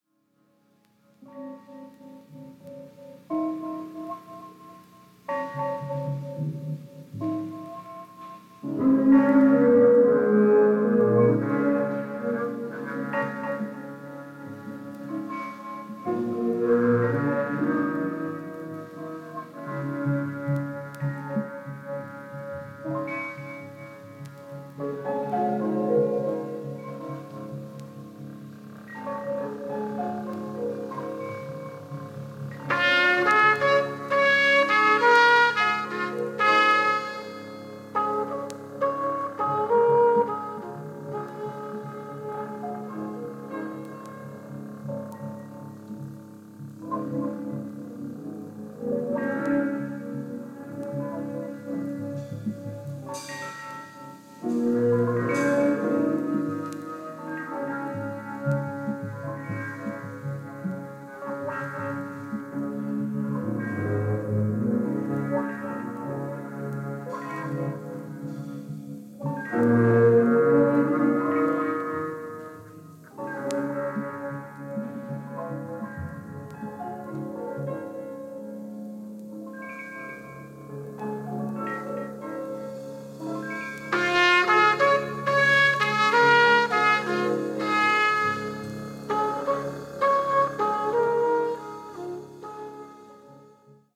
Bass
Electric Piano
Percussion